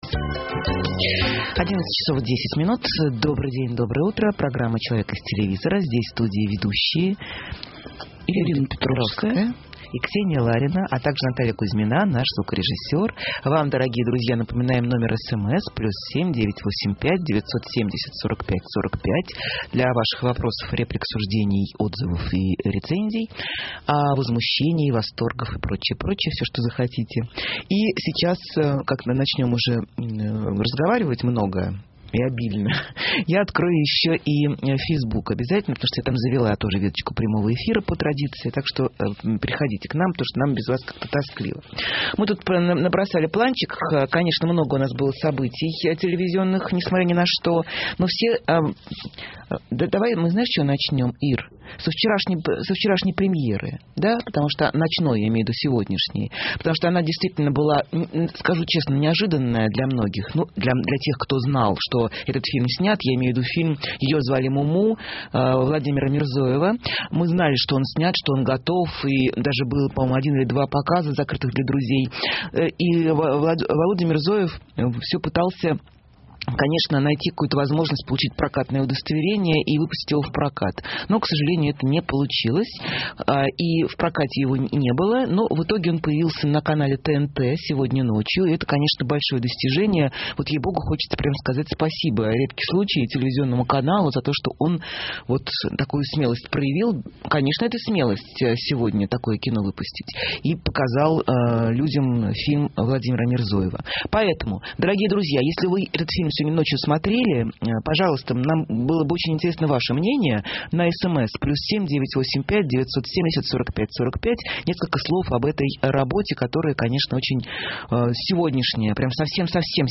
В прямом эфире радиостанции «Эхо Москвы» - Ирина Петровская, телекритик